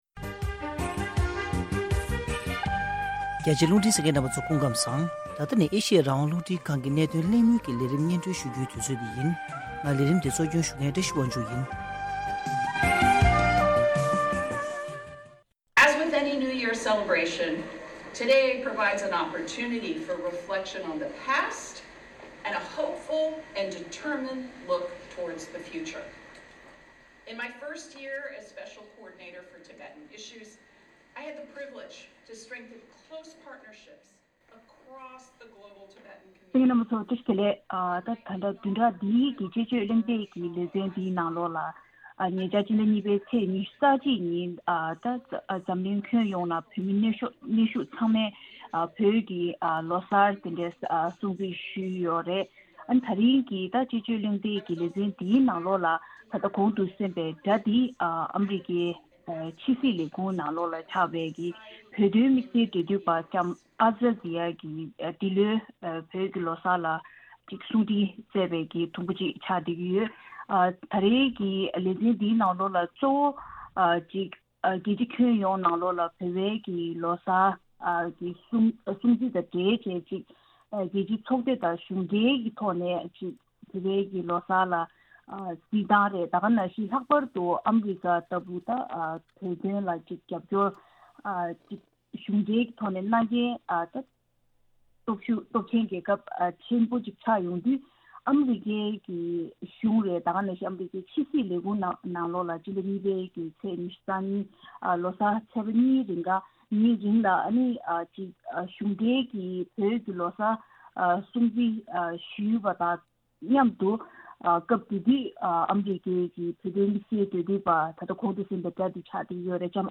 ཐེངས་འདིའི་བདུན་རེའི་དཔྱད་བརྗོད་གླེང་སྟེགས་ཀྱི་ལས་རིམ་ནང་།